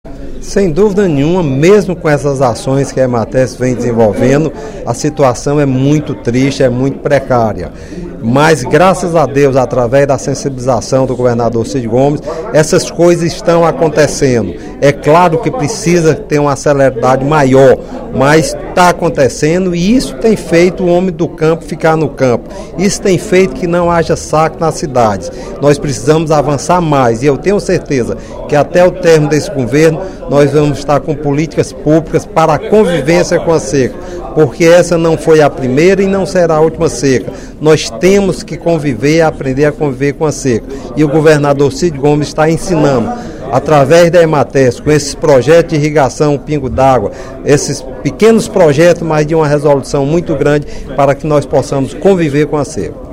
O presidente da Comissão de Agropecuária da Assembleia Legislativa, deputado Hermínio Resende (PSL), ressaltou, no primeiro expediente da sessão plenária desta quinta-feira (13/12), a importância da atuação da Empresa de Assistência Técnica e Extensão Rural do Ceará (Ematerce) para amenizar dos efeitos da seca deste ano.